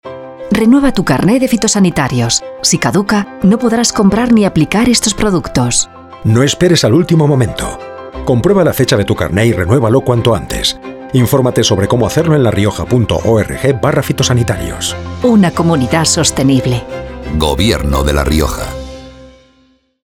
Elementos de Campaña Cuñas radiofónica Cuña de 20".